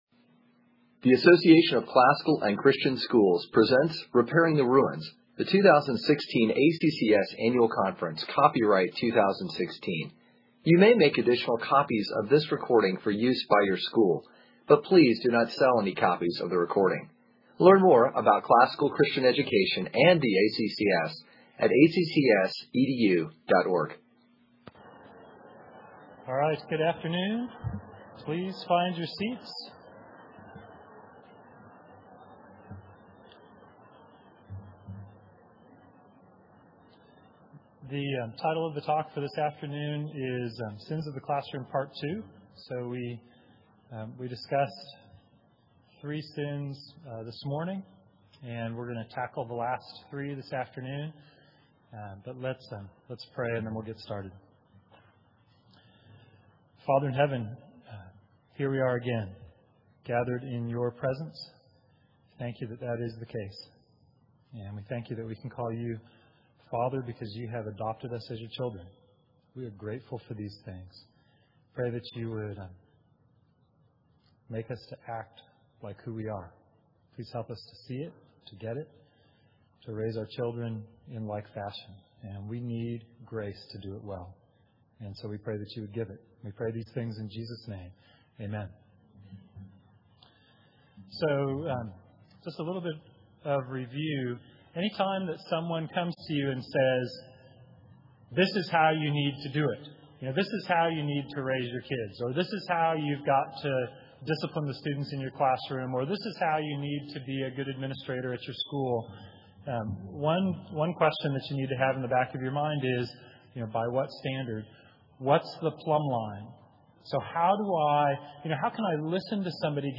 2016 Workshop Talk | 2016 | All Grade Levels, Virtue, Character, Discipline